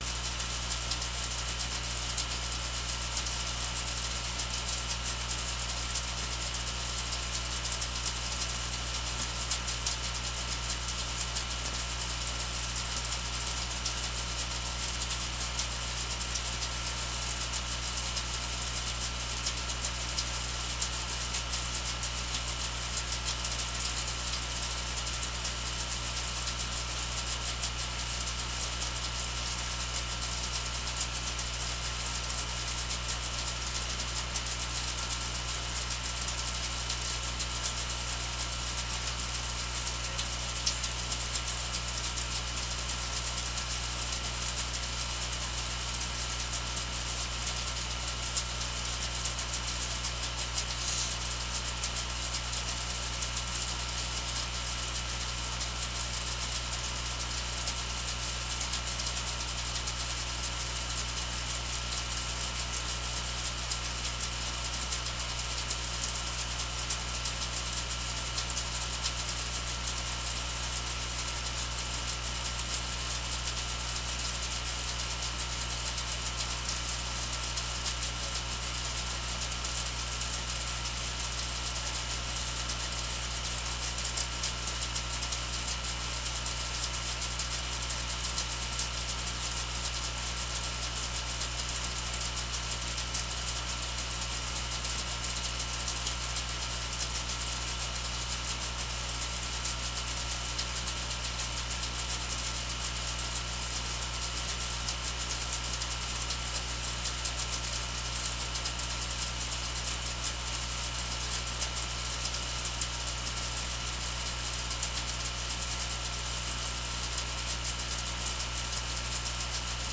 使用Zoom H6 XY Mic录制。
在森林里重重的雨和雷声。